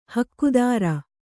♪ hakkudāra